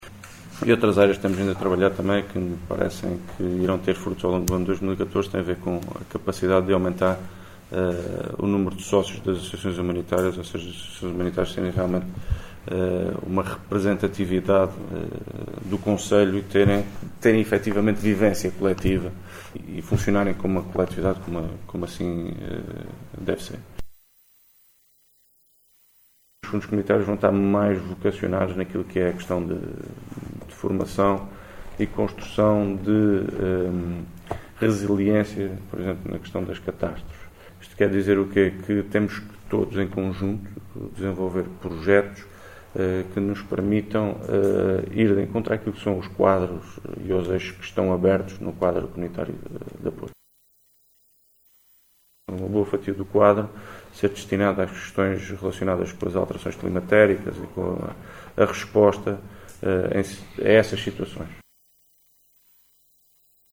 Luís Cabral, que intervinha na abertura da reunião dos Comandantes das Corporação de Bombeiros do Açores, em Angra do Heroísmo, afirmou que o Governo dos Açores tenciona lançar uma campanha tendo em vista aumentar o número de sócios dos bombeiros, “ampliando a sua representatividade” e contribuindo também, deste modo, para que possam ter “uma gestão equilibrada das suas contas”.